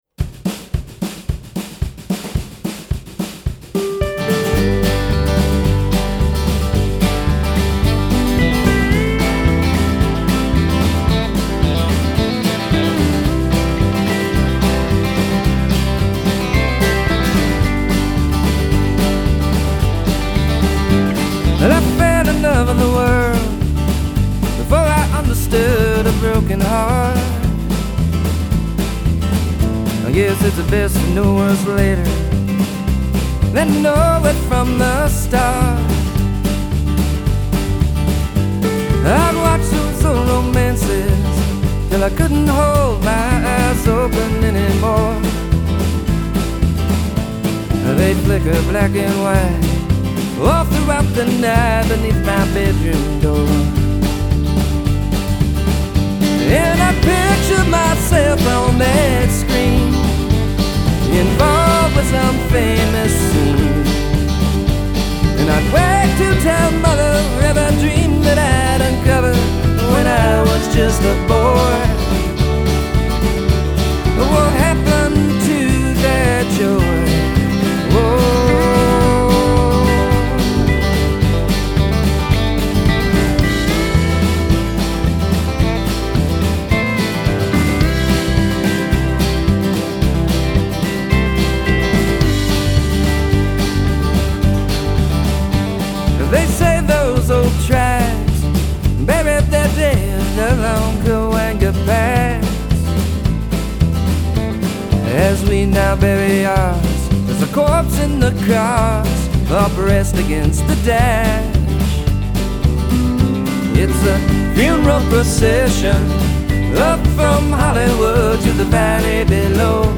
Guitars/Lead Vox
Bass & BG Vox
Drums
Pedal Steel Guitar